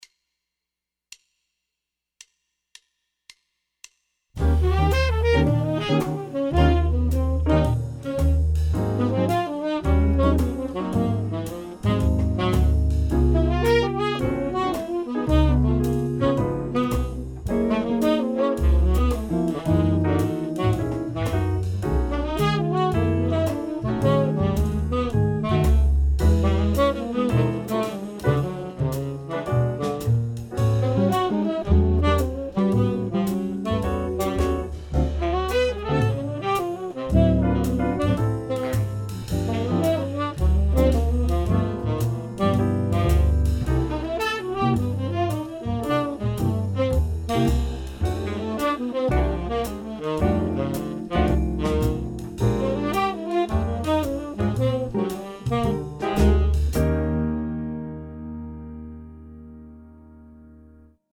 To derive the maximum benefit, practice this pattern in all 12 keys around the circle of fifths using the background track provided below.